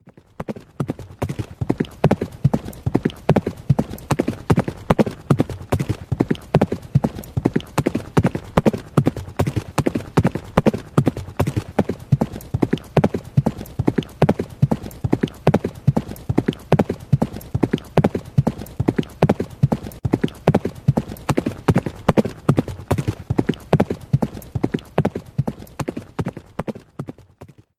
Klingelton Pferdegalopp
Beschreibung: Unser kostenloser MP3-Klingelton bietet das authentische Geräusch von galoppierenden Pferden – ideal für alle Pferdefreunde und Abenteuerlustigen.
klingelton-pferdegalopp-de-www_tiengdong_com.mp3